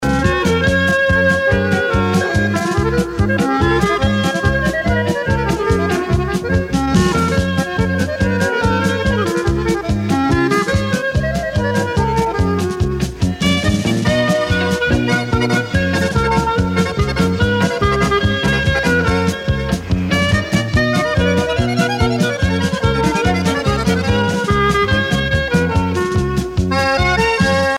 danse : paso musette
Pièce musicale éditée